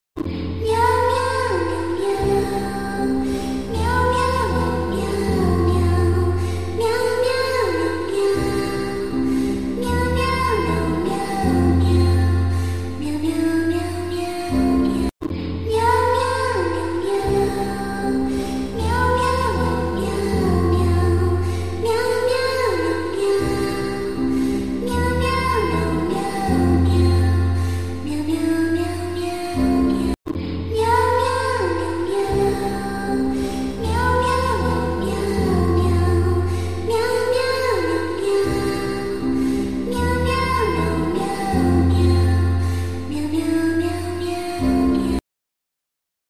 kucing menangis di kejar hantu